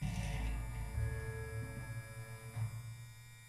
剃刀，打击乐器组 " hi norm hi norm
描述：电动剃须刀，金属棒，低音弦和金属罐。
Tag: 剃须刀 重复 电机 金属 金属加工 发动机